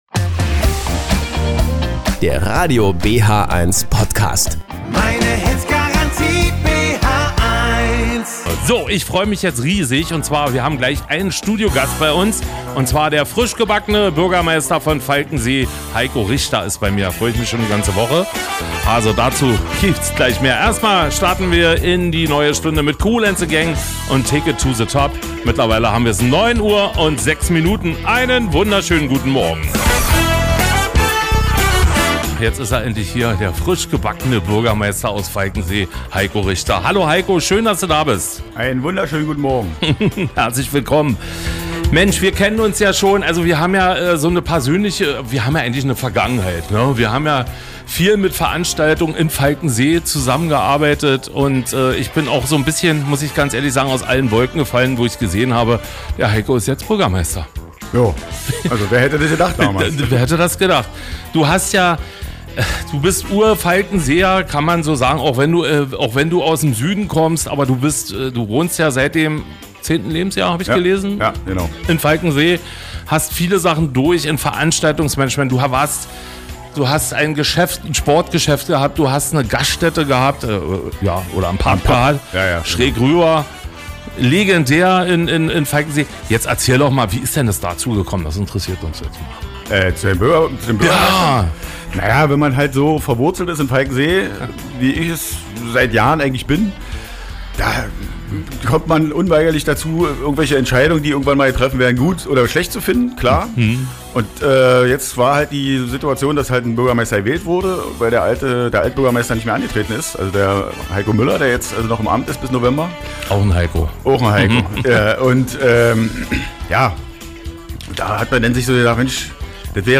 Der neu gewählte Bürgermeister der Stadt Falkensee im Gespräch